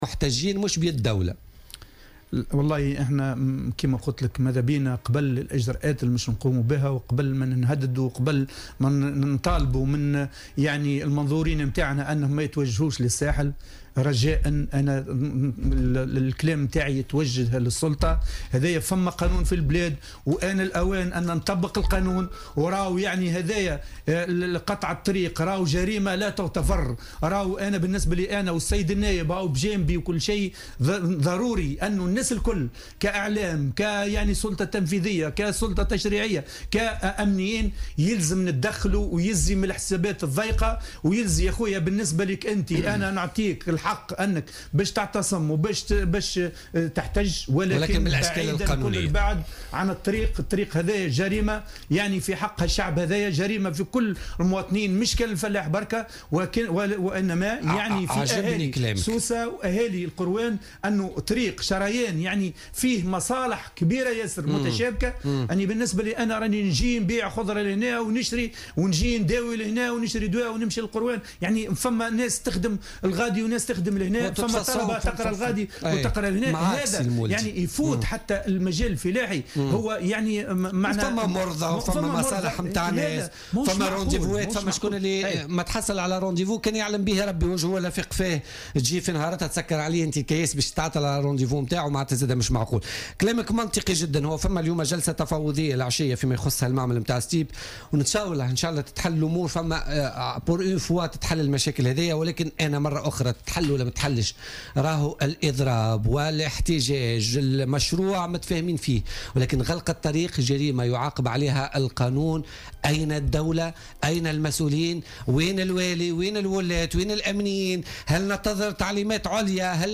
أكد النائب بمجلس الشعب عماد أولاد جبريل ضيف بوليتيكا اليوم الأربعاء 7 ديسمبر 2016 أنه لا يمكن انكار الظلم والتعسف الكبير الموجود في مصنع "الستيب" ولكن هذا لا يعطي الحق لأي كان لغلق طريق يمثل شريان حيوي .